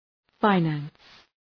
{faı’næns}